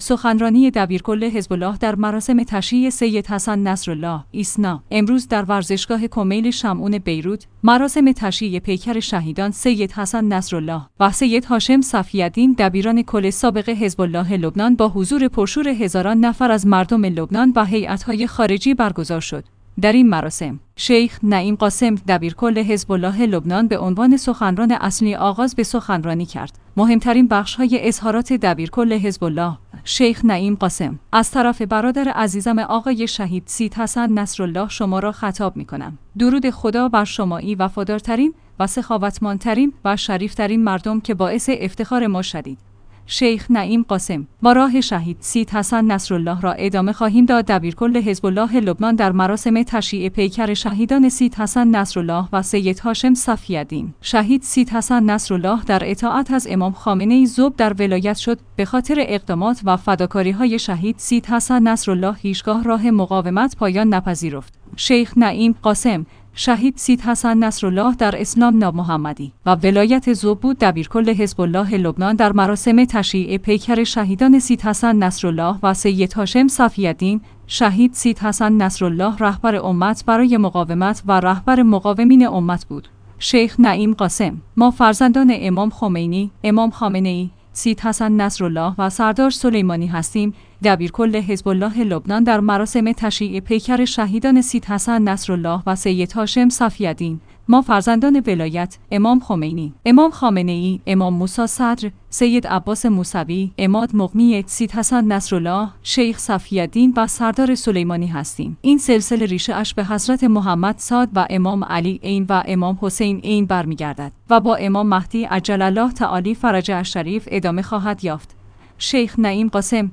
سخنرانی دبیرکل حزب الله در مراسم تشییع سید حسن نصرالله